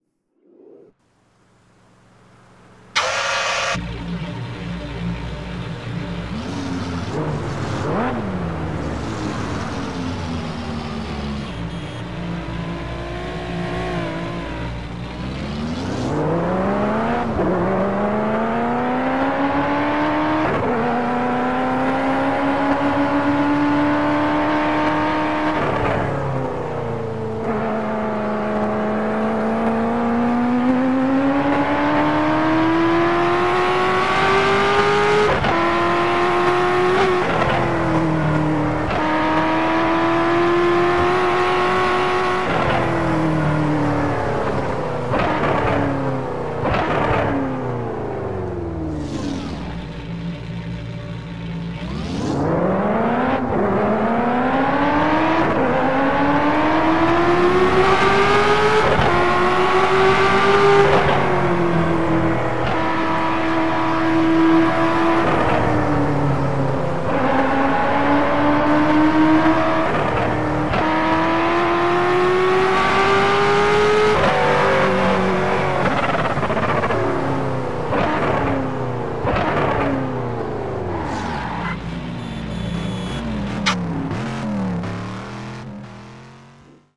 TDU 1 - Sound mods